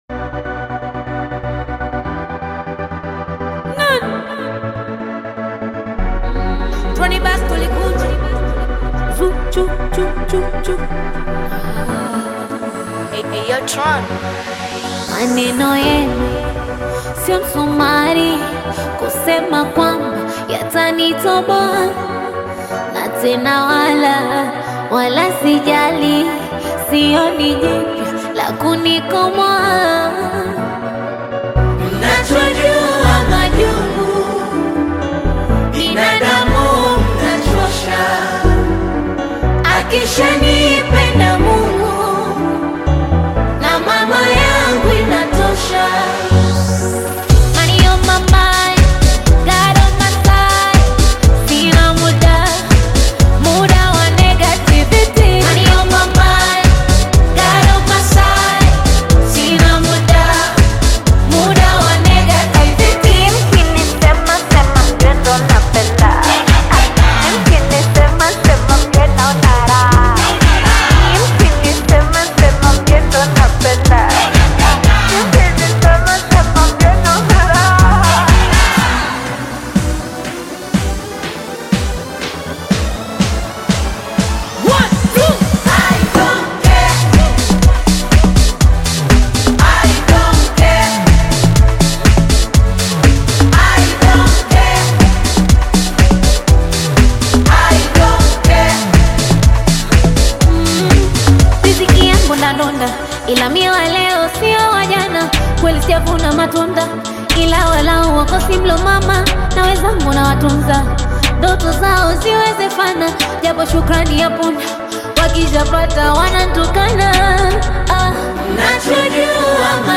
Bongo Flava music track
Bongo Flava song
This catchy new song